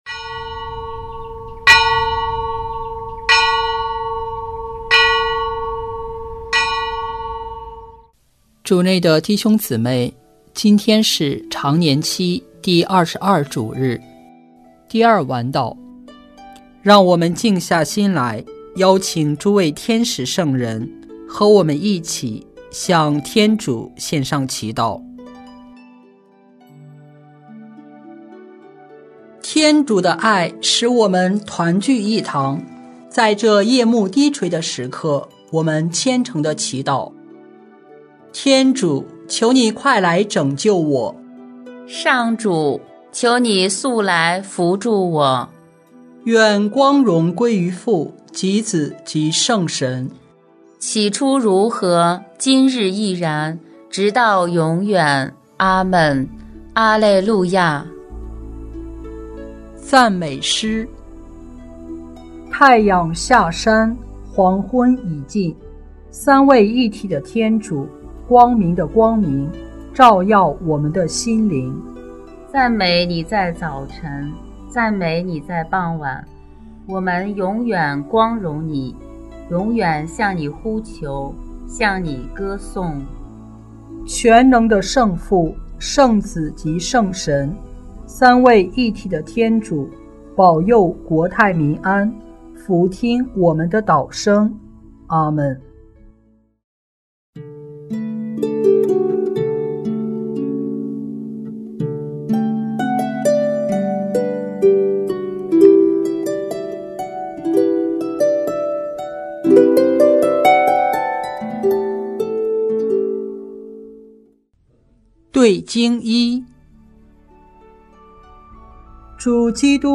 圣咏吟唱 圣咏 109:1-5, 7 默西亚是君王兼司祭 “基督必须为王，直到把一切仇敌屈服在他的脚下。”